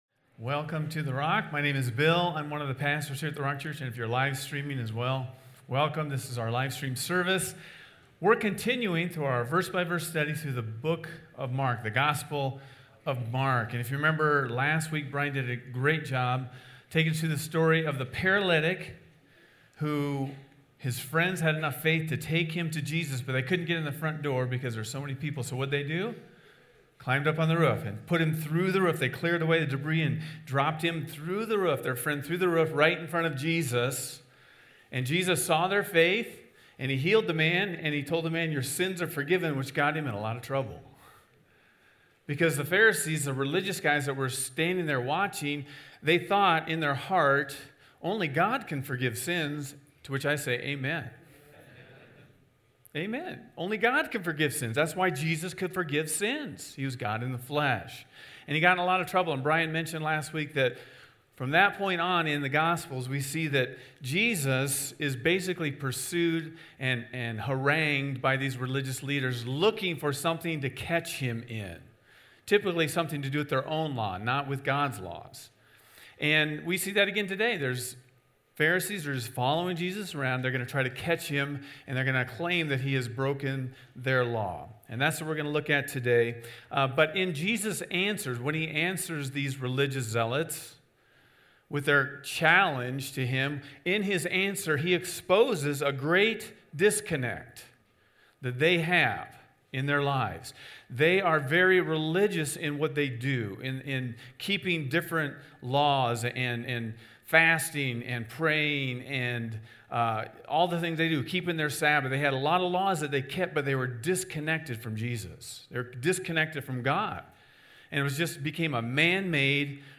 A message from the series "The Gospel of Mark ."